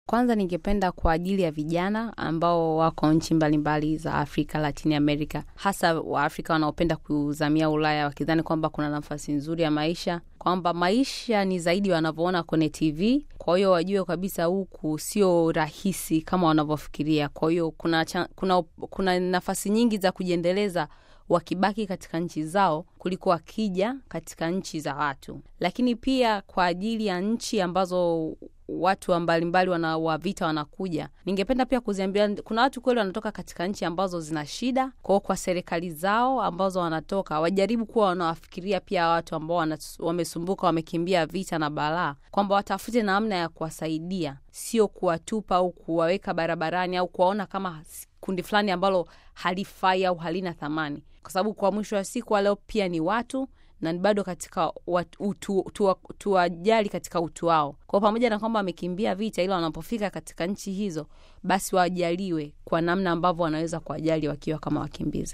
Katika mahojiano maalum na Radio Vatican kuhusu tatizo la vijana kutoka nchi zilizoendelea na kwa namna ya pekee kutoka Barani Afrika kupenda kuzamia Ulaya na Marekani wakitafuta malisho ya kijani kibichi anasema, kila mtu anayo haki ya kubaki na kuishi katika nchi yake mwenyewe.